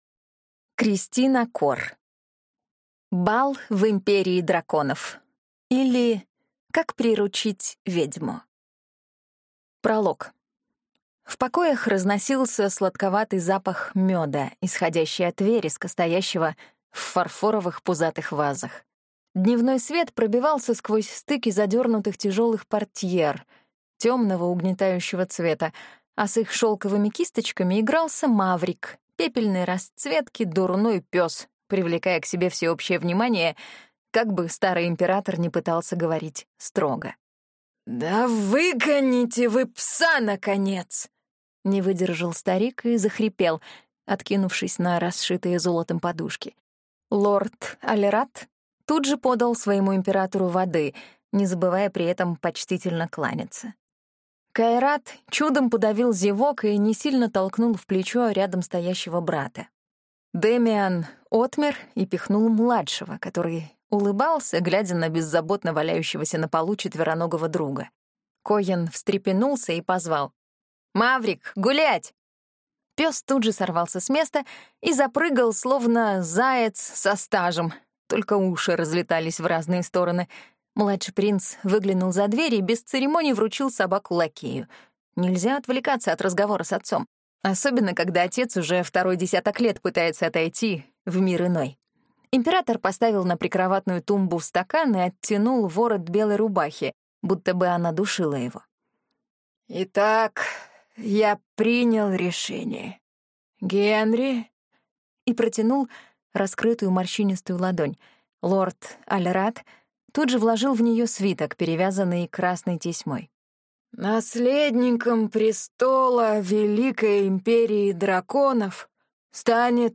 Аудиокнига Бал в империи драконов, или Как приручить ведьму | Библиотека аудиокниг